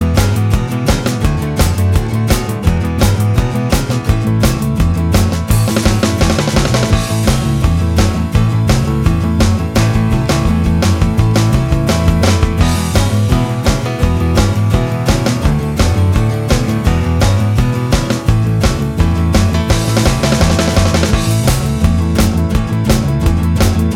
No Rhythm Electric Or Solo Guitar Pop (1980s) 2:47 Buy £1.50